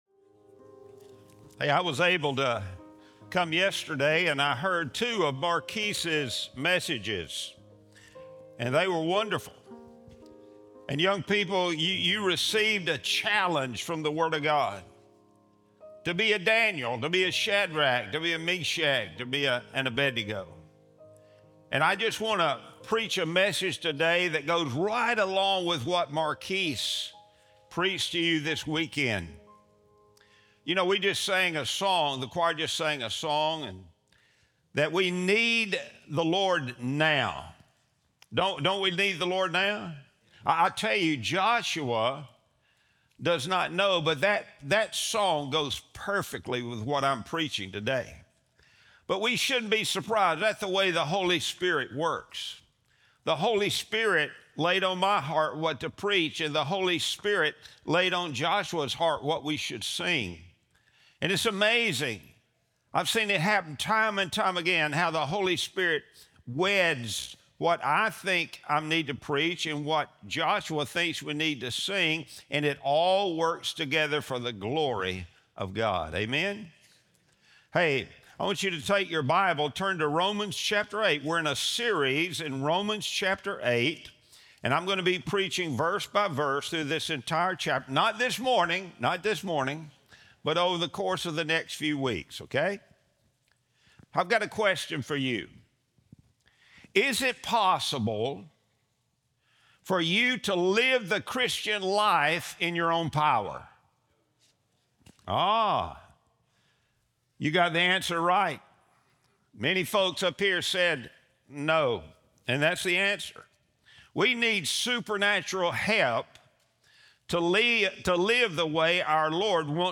Sunday Sermon | February 8, 2026